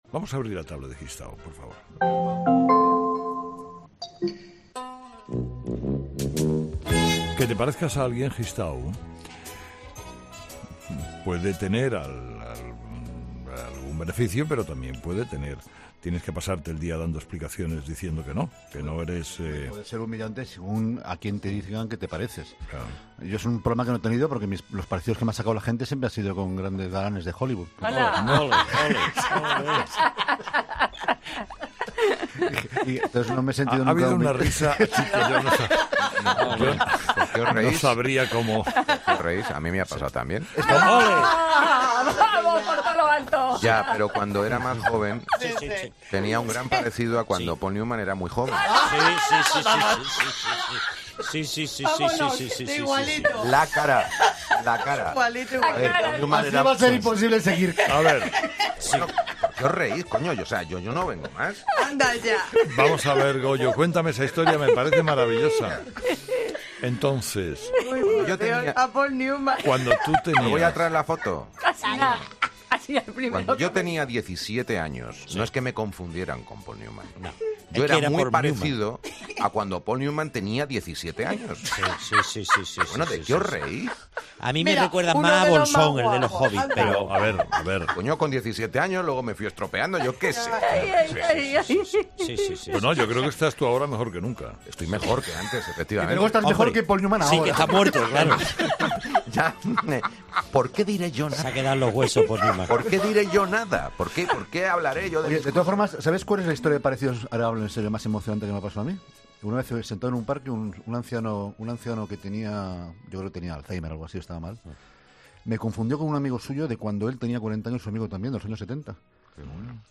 El tráfico de niños mutilados en Madrid para usarlos en la mendicidad, ha sido el tema elegido por David Gistau para su 'tablet ' de este lunes.